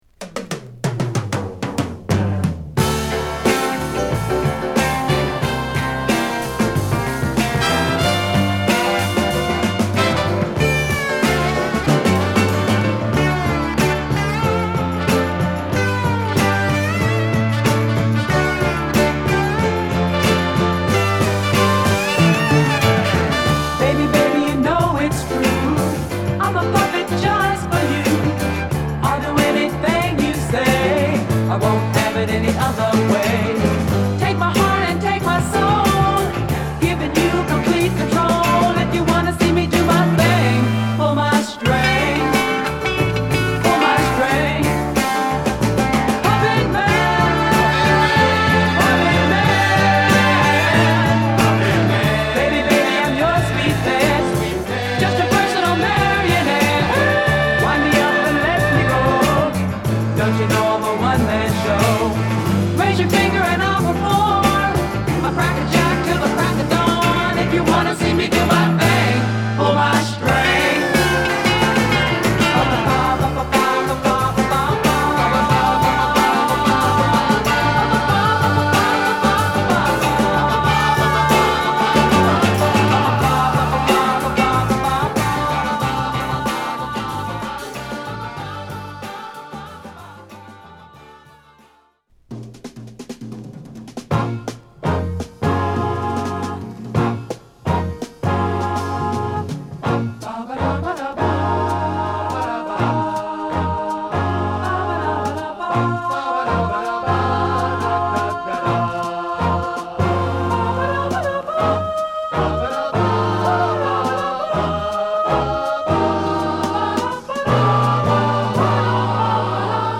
ソフトロック〜ソウル／R&Bまで対応！